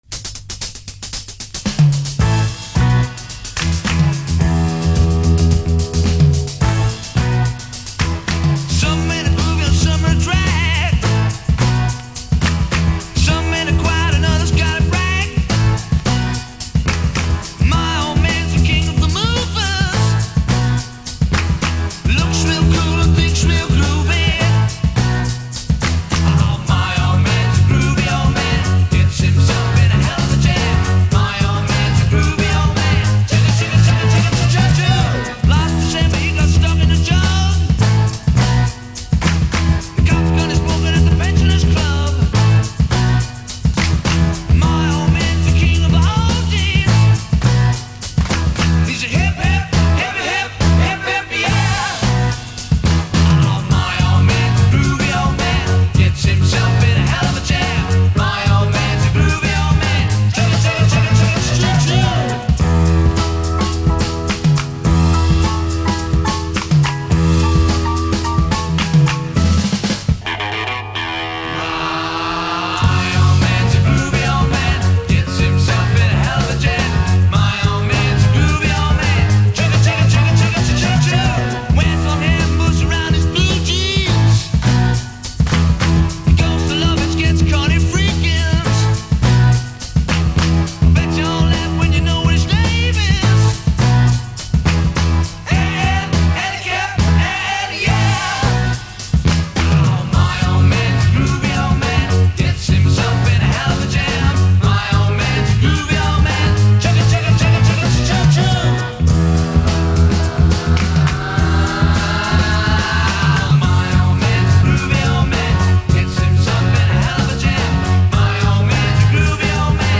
penned groovy psych pop !!
試聴 (実際の出品物からの録音です)